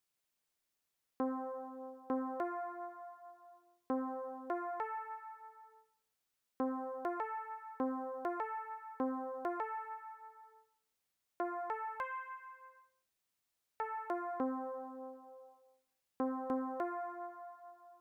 AdLib MUS